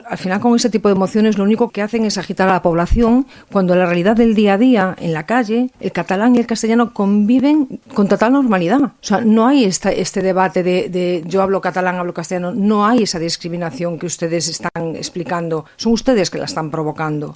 La popular Celine Coronil va negar en la seva intervenció que la llengua estigui discriminada a Catalunya, tot retraient que aquest tipus d’iniciatives agiten a la població.